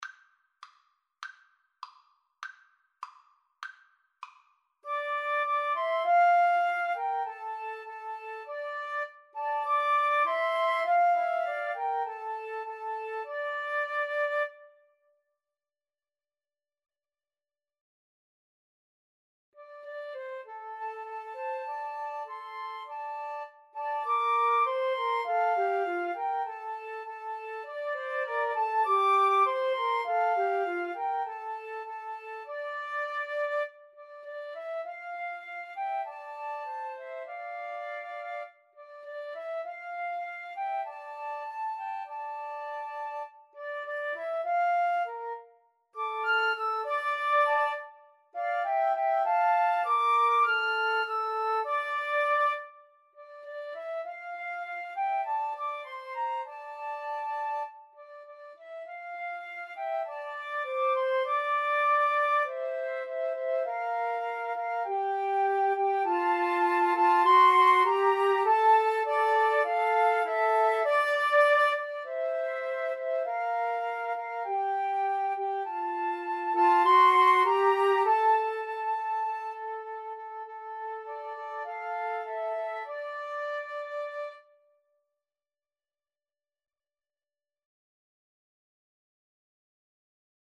Moderato
2/4 (View more 2/4 Music)
Arrangement for Flute Trio
Classical (View more Classical Flute Trio Music)